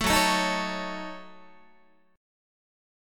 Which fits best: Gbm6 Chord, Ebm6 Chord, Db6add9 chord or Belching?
Gbm6 Chord